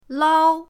lao1.mp3